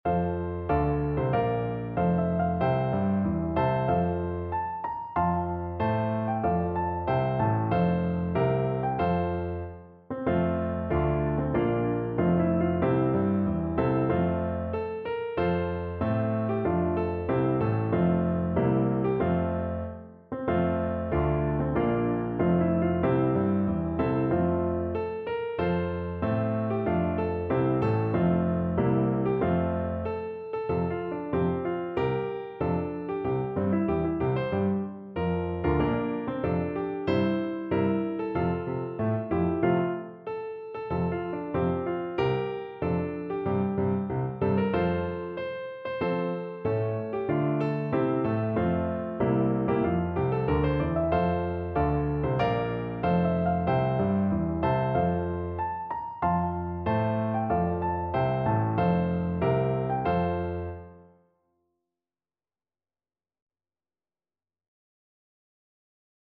No parts available for this pieces as it is for solo piano.
=94 Moderato maestoso
4/4 (View more 4/4 Music)
Piano  (View more Intermediate Piano Music)